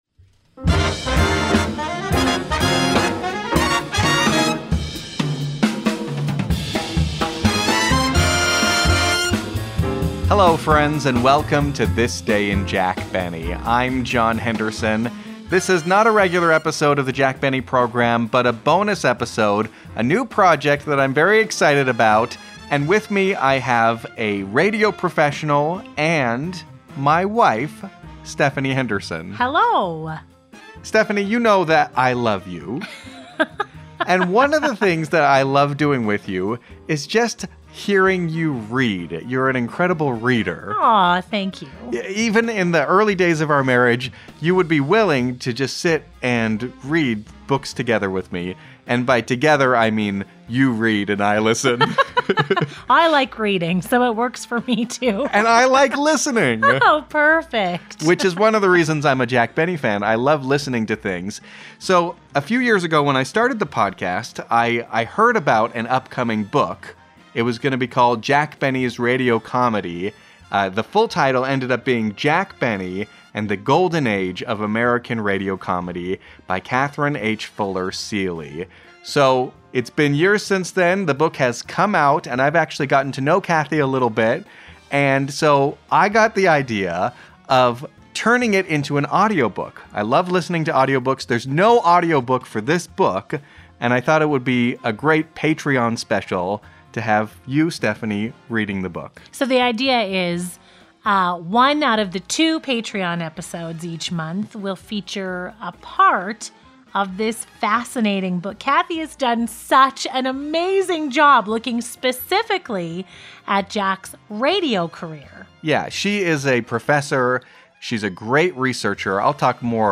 Jack Benny Audiobook
jack-benny-and-the-golden-age-of-american-radio-comedy-00-introduction.mp3